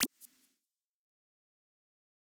generic-hover-softer.wav